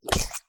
squish01.ogg